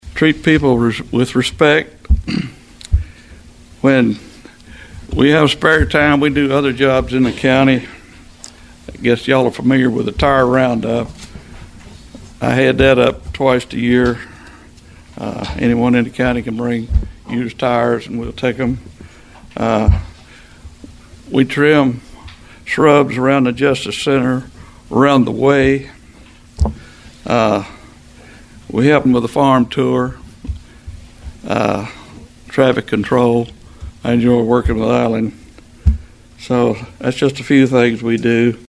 Trigg County Republican Jailer candidates touted experience, community service, and training during the recent republican party meet the candidates event in Cadiz.